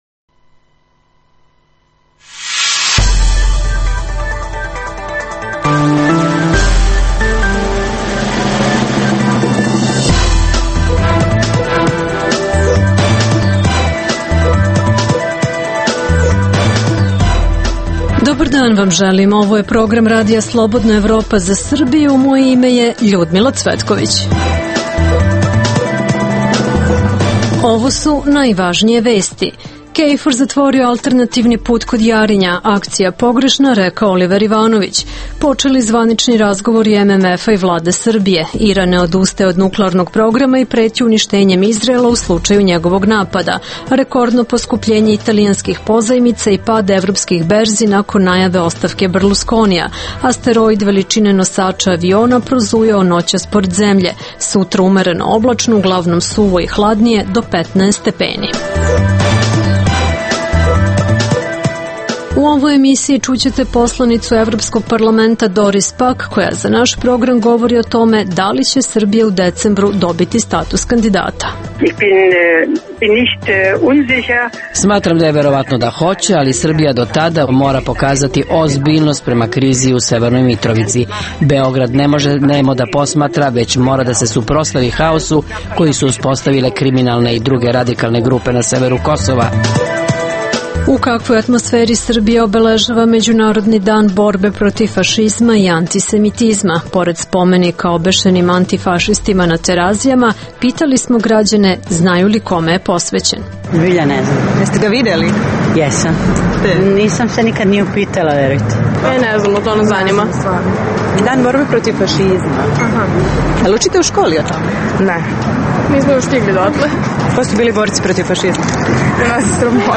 U emisiji možete čuti: -Za RSE govori poslanica Evropskog parlamenta Doris Pak o tome da li će Srbija u decembru dobiti status kandidata. -Istraživali smo da li će kriza u Italiji ugroziti Fiatovu investiciju u Kragujevcu vrednu oko milijardu evra.